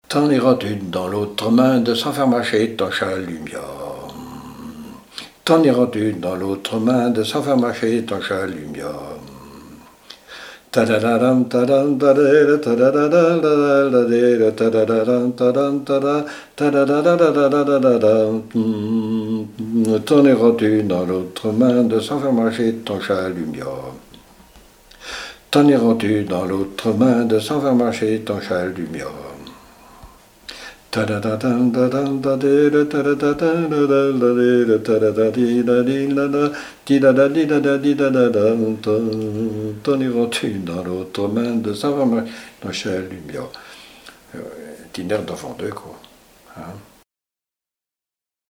Rochetrejoux ( Plus d'informations sur Wikipedia ) Vendée
Laforte : non-référencée - V, F-** Coirault : non-référencée - 0074** Thème : 0074 - Divertissements d'adultes - Couplets à danser Résumé : T'en iras-tu dans l'autre monde, sans faire marcher ton chalumia.
Fonction d'après l'analyste danse : branle : avant-deux
Répertoire de chants brefs pour la danse
Catégorie Pièce musicale inédite